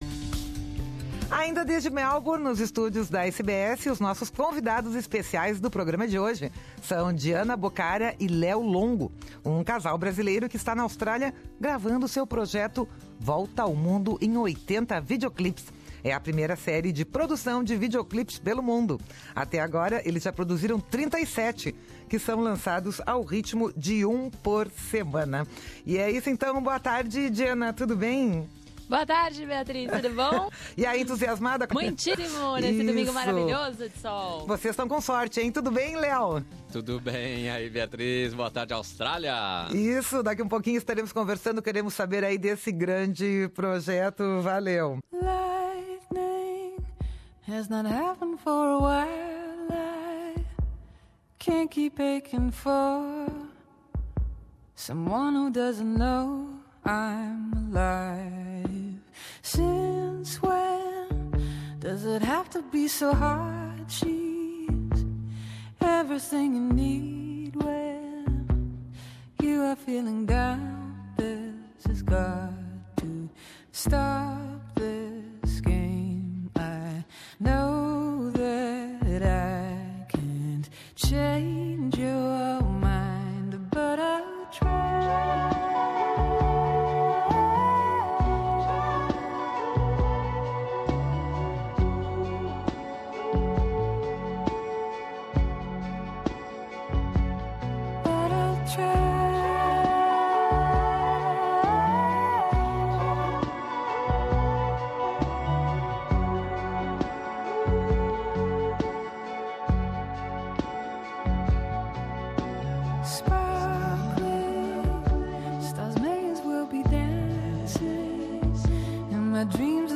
nos estúdios da Rádio SBS em Melbourne